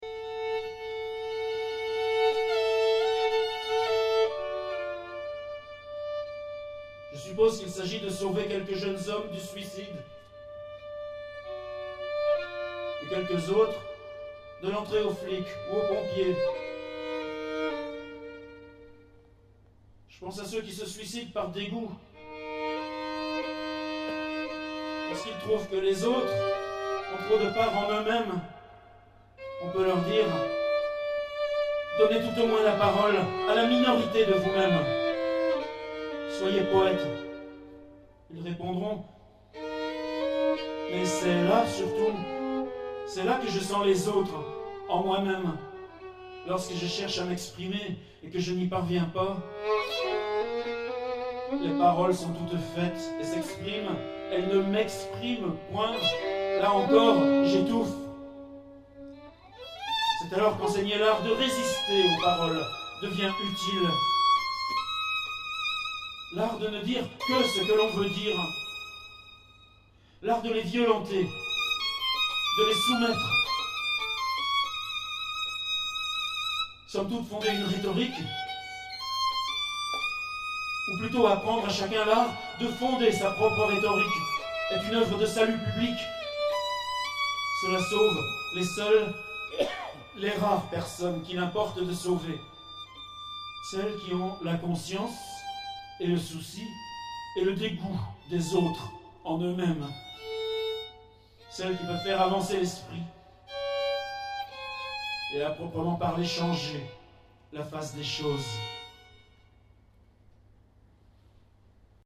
Dominique Pifarély, mandoline